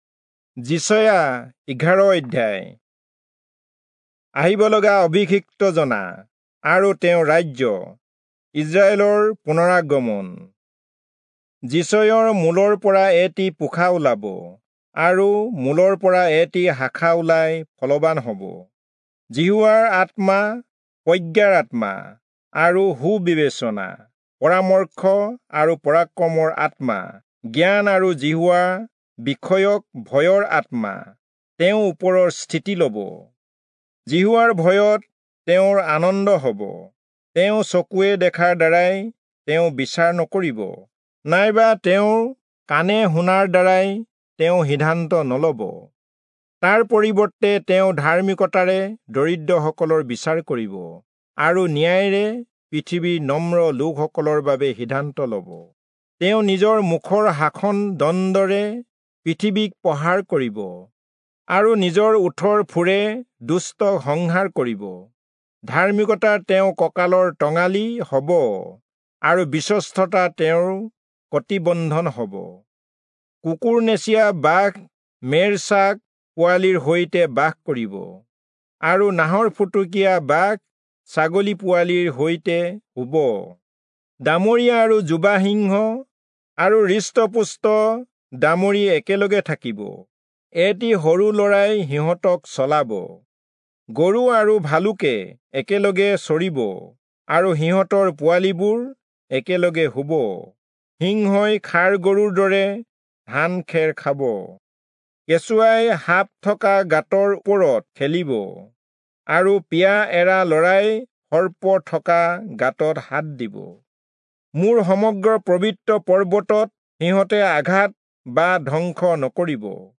Assamese Audio Bible - Isaiah 47 in Gntbrp bible version